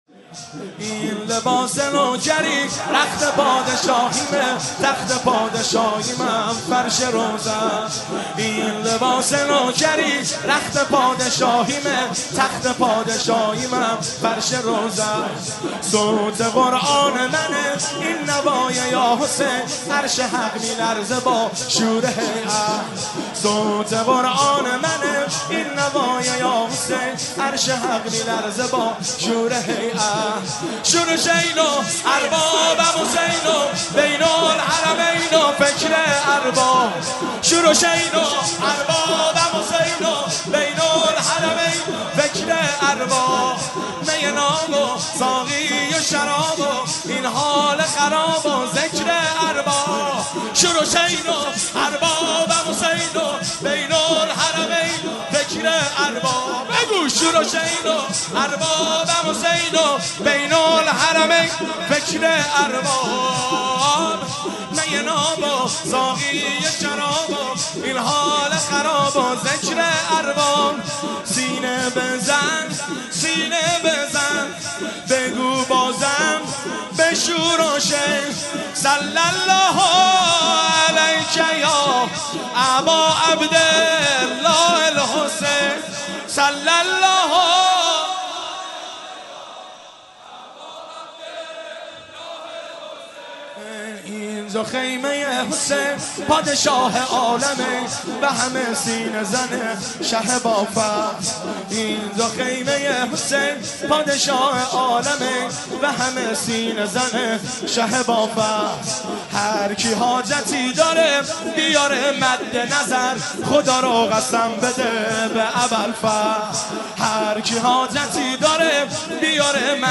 مداحی این لباس نوکری(شور)
شب هفتم محرم 1392
هیئت خادم الرضا(ع) قم